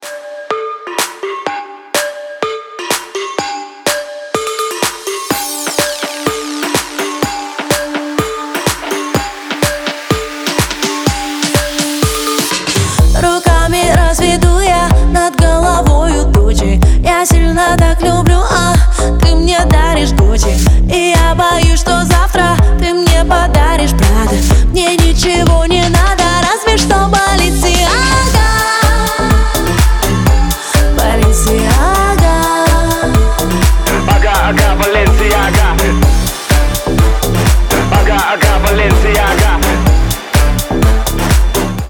• Качество: 128, Stereo
заводные
красивая мелодия
Dance Pop
энергичные
красивый женский голос